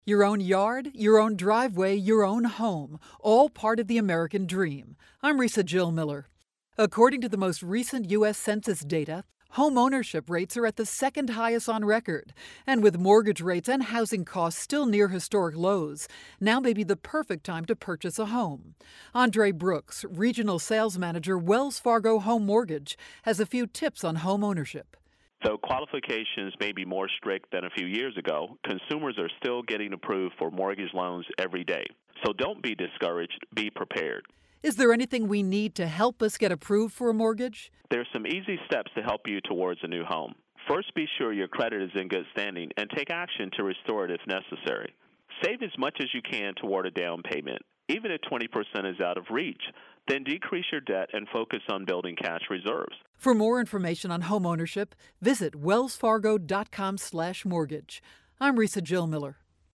July 13, 2012Posted in: Audio News Release